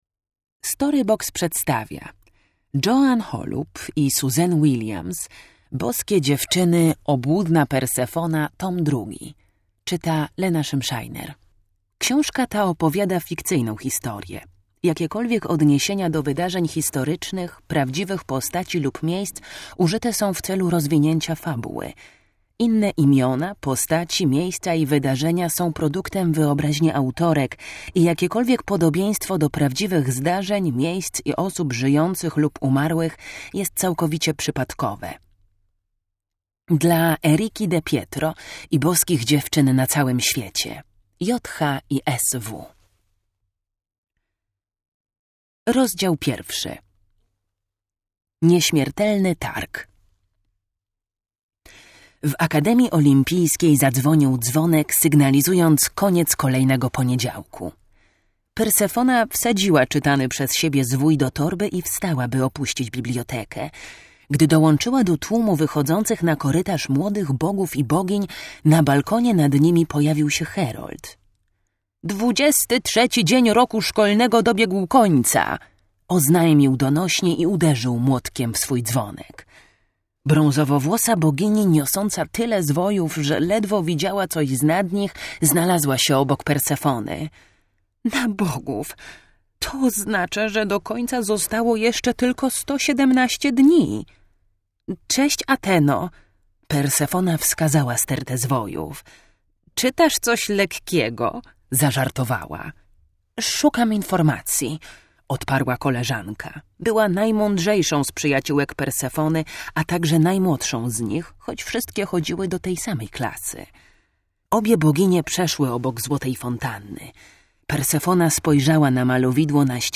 Audiobook + książka Boskie dziewczyny Tom 2 Obłudna Persefona, Holub Joan, Williams Suzanne.